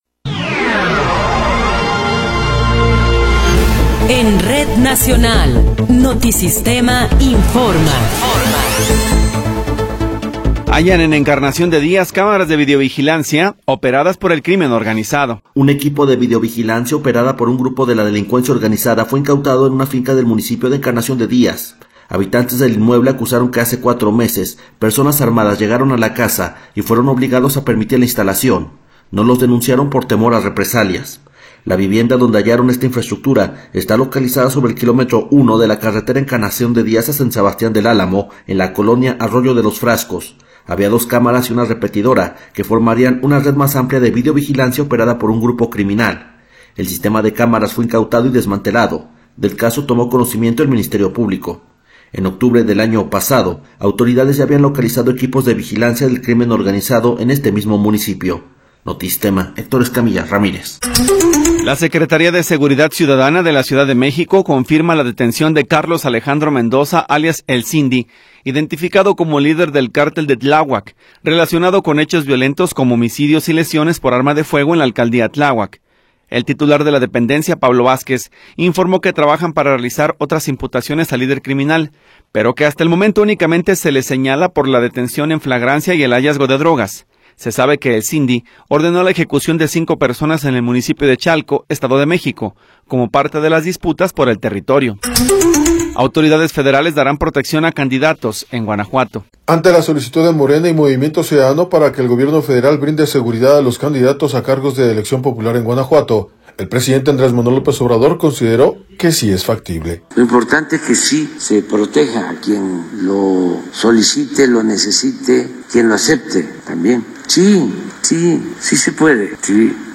Noticiero 10 hrs. – 4 de Abril de 2024
Resumen informativo Notisistema, la mejor y más completa información cada hora en la hora.